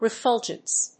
音節re・ful・gence 発音記号・読み方
/rɪfˈʊldʒəns(米国英語), ɹɪˈfʌl.dʒənts(英国英語)/